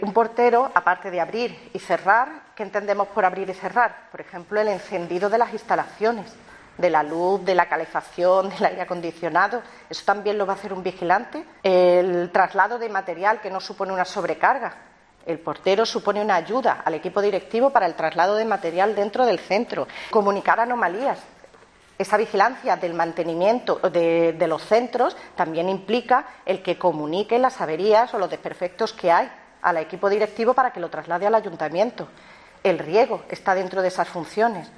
En estos momentos, hay 17 colegios públicos donde estas plazas están sin cubrir, “lo que significa que cientos de escolares y de familias que apuestan por la enseñanza pública en nuestra ciudad no cuentan con este servicio”, ha señalado en rueda de prensa la edil socialista.